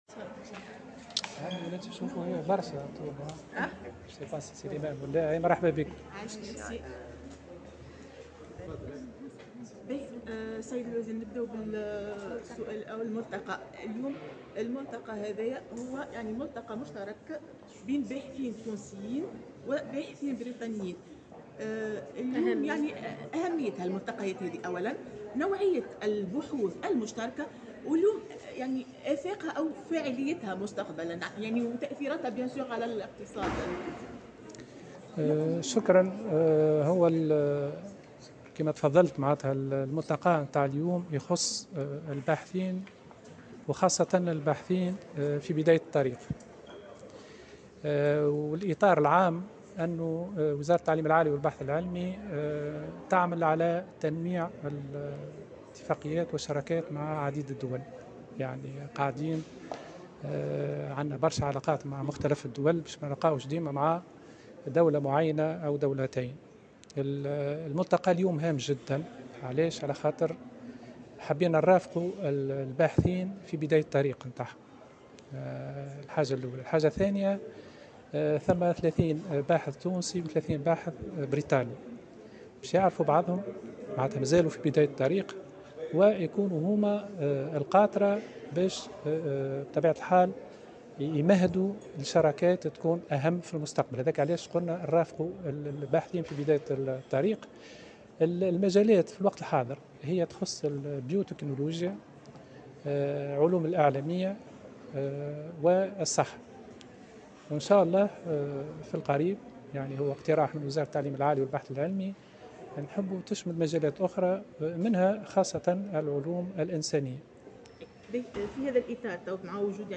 وأكد وزير التعليم العالي والبحث العلمي منصف بوكثير في تصريح لمراسلة الجوهرة اف ام بالمناسبة، أن الباحثين المستفيدين من هذه اللقاءات هم باحثون في بداية مسيرتهم، مختصون في البيوتكنولوجيا وعلوم الإعلامية والصحة، على أن تشمل مستقبلا مجالات اخرى مثل العلوم الإنسانية.